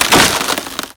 wood_impact.wav